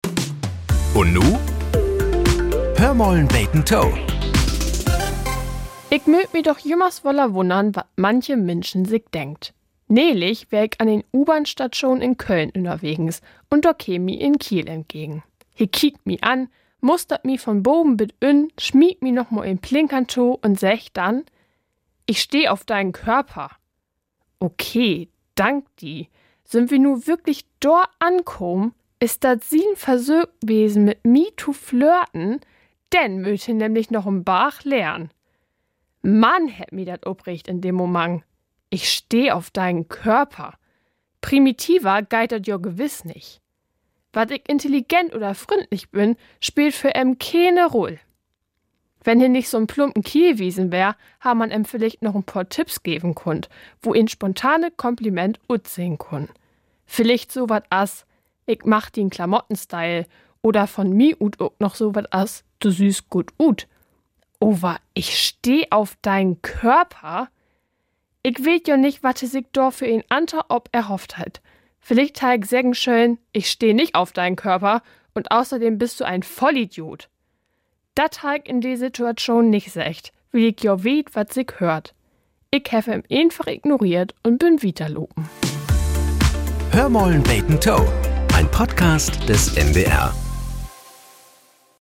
Nachrichten - 12.03.2025